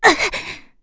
FreyB-Vox_Damage_02.wav